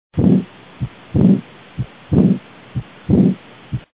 Südametoonide helisalvestused (H.)
Aordiklapi puudulikkus  Vere tagasivalgumine diastolis.